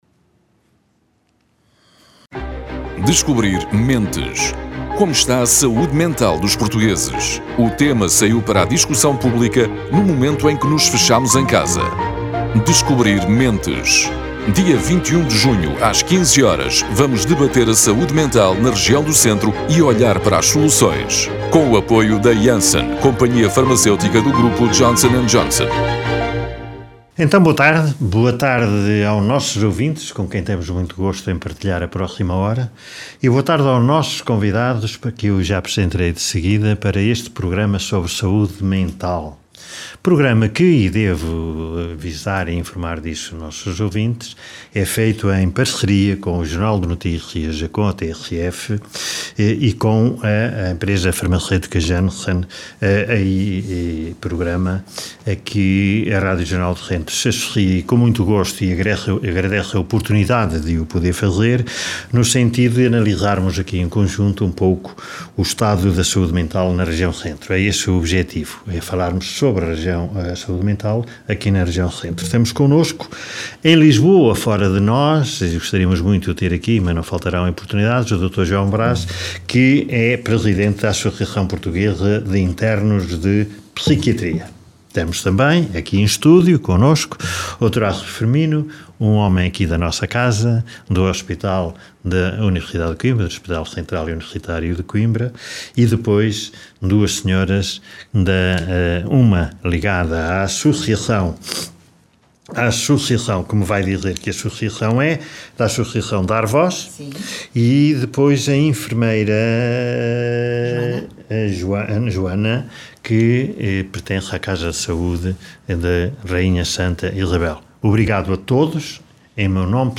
Oiça aqui o debate realizado hoje, em directo, na Rádio Regional do Centro, sob o mote “Descobrir Mentes”.